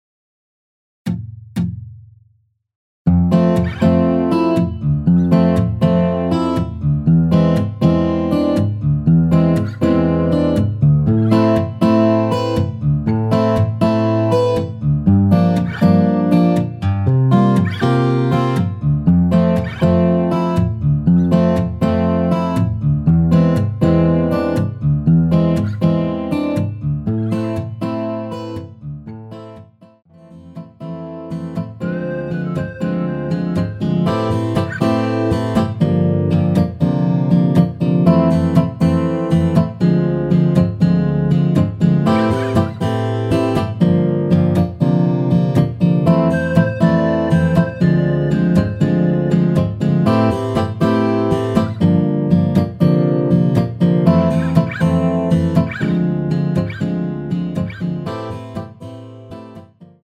전주 없는 곡이라 전주 2박 만들어 놓았습니다.~
◈ 곡명 옆 (-1)은 반음 내림, (+1)은 반음 올림 입니다.
앞부분30초, 뒷부분30초씩 편집해서 올려 드리고 있습니다.
중간에 음이 끈어지고 다시 나오는 이유는